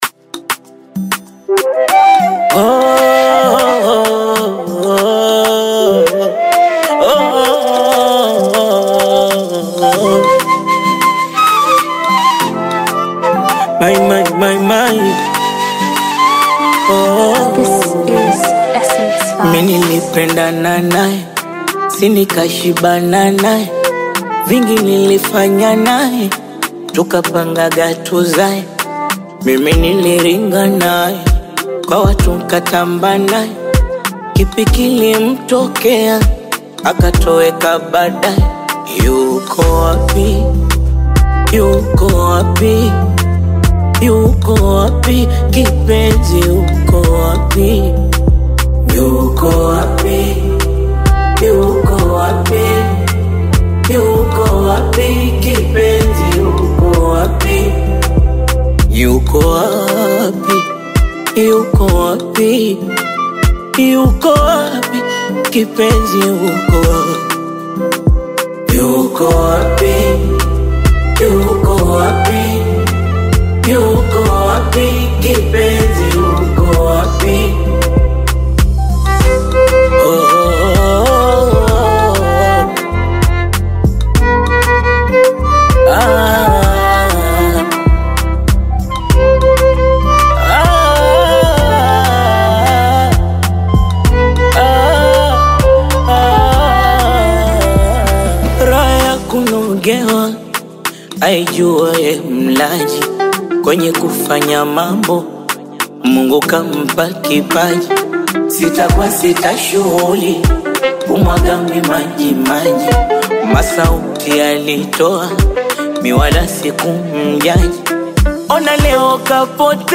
smooth, captivating melody